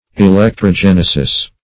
Search Result for " electrogenesis" : The Collaborative International Dictionary of English v.0.48: Electrogenesis \E*lec`tro*gen"e*sis\, n. [Electro- + genesis.]